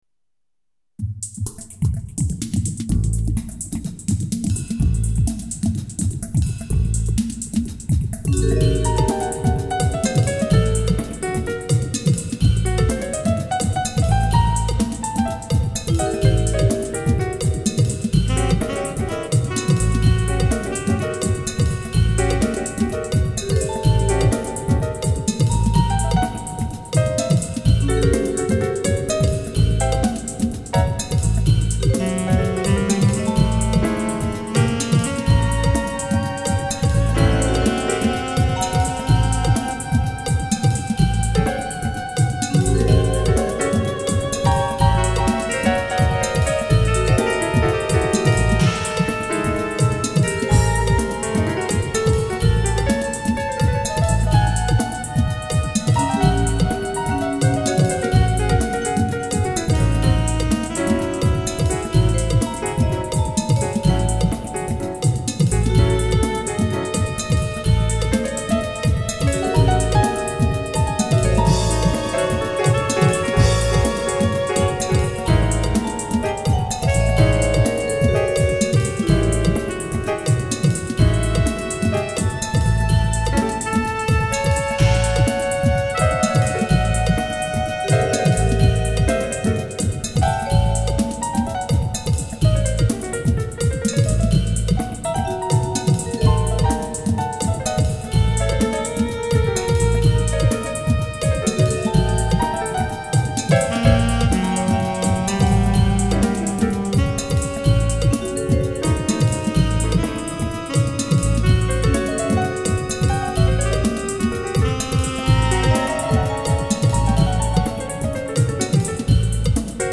dance/electronic
Jazz